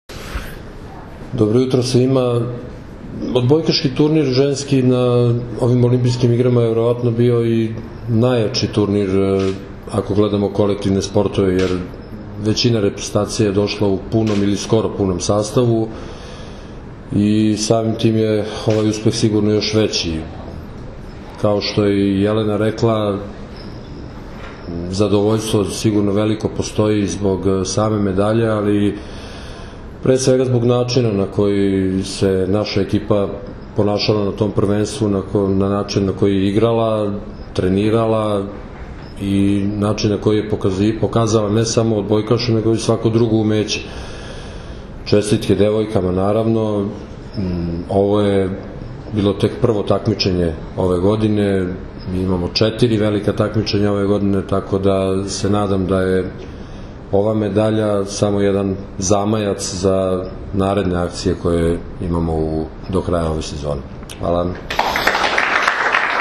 Olimpijski komitet Srbije je organizovao konferenciju za novinare u salonu “Beograd” aerodroma “Nikola Tesla”, na kojoj su se predstavnicima medija obratili Jelena Nikolić, kapiten seniorki Srbije, i Zoran Terzić, prvi trener seniorki Srbije.
IZJAVA ZORANA TERZIĆA